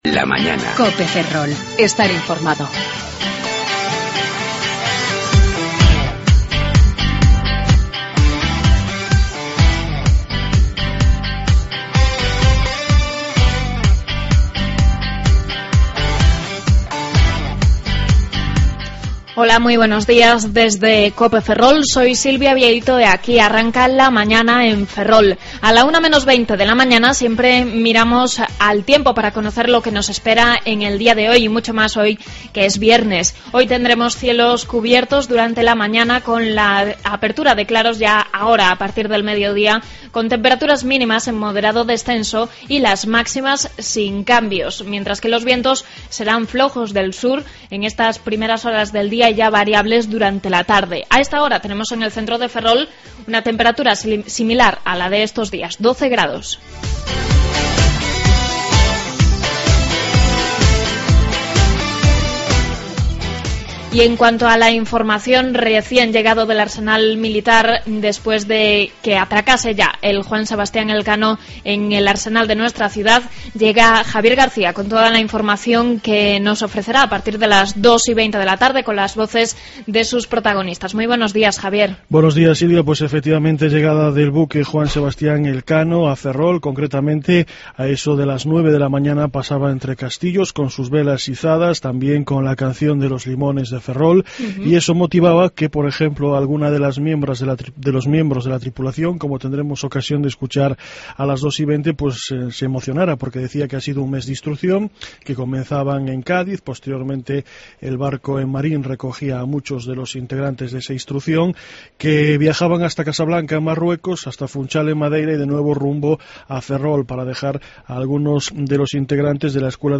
AUDIO: Avances informativos y contenidos de Ferrol, Eume y Ortegal.